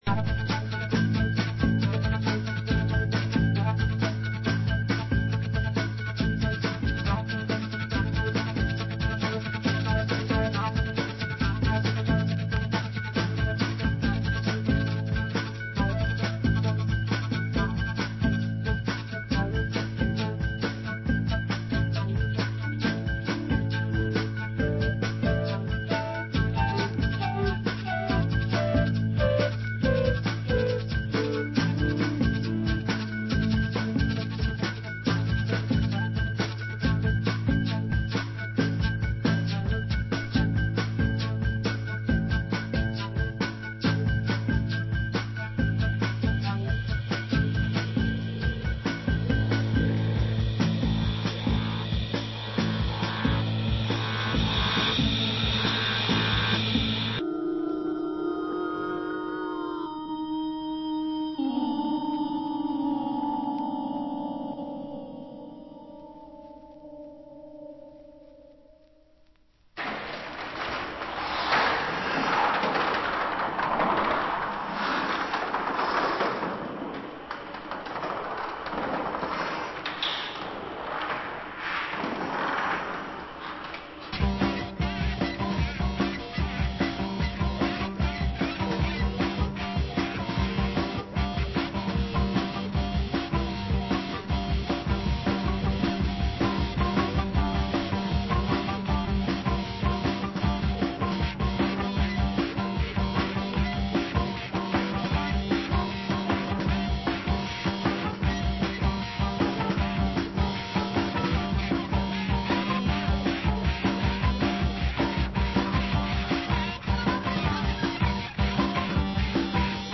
Genre: Electro